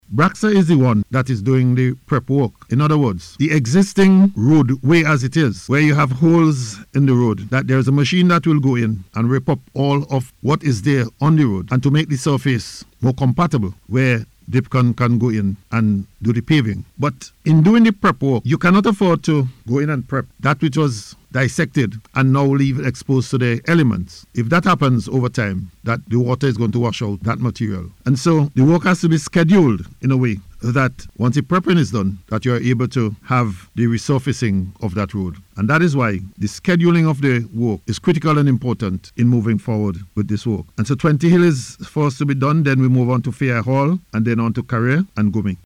Speaking on radio on the status of the project, Minister Daniel noted that BRAGSA will undertake the preparatory works before contractor Dipcon moves in to begin final paving.